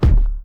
crate_open.wav